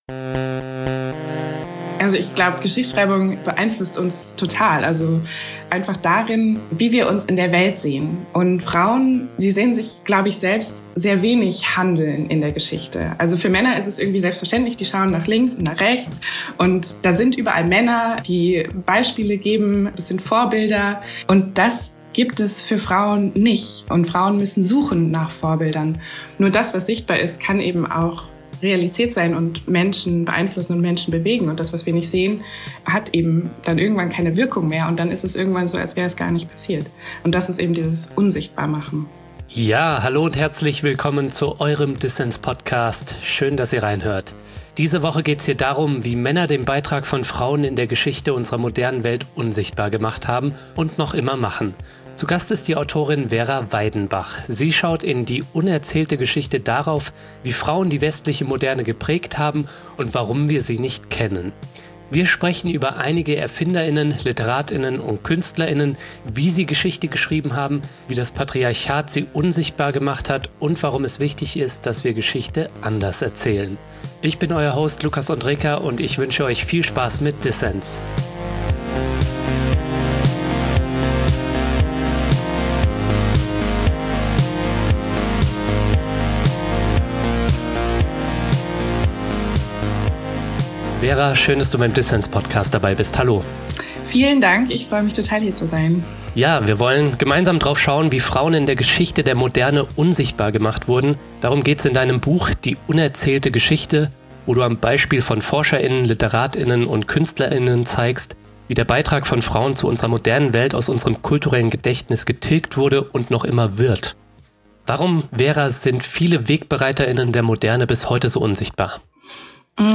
Ein Gespräch über das Patriarchat in Schulbüchern und eine neue Art der Geschichtsschreibung.